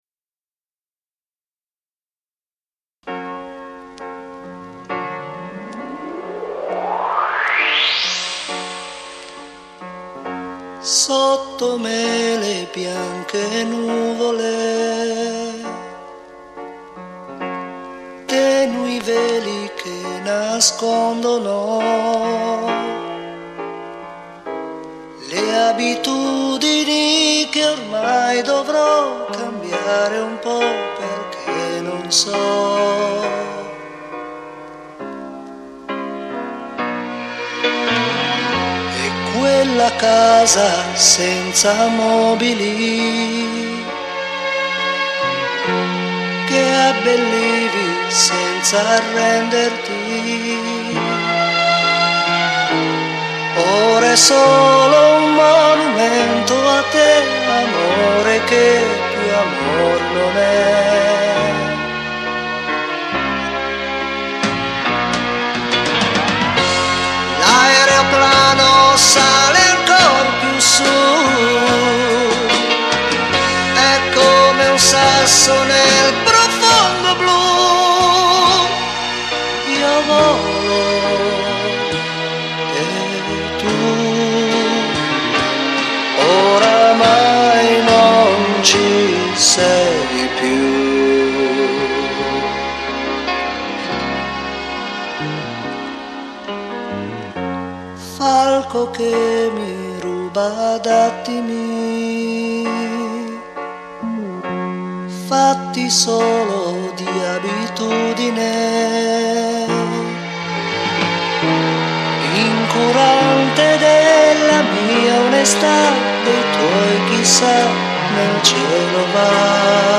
CHITARRA ACUSTICA, ELETTRICA
PERCUSSIONI
BASSO
PIANO, MOOG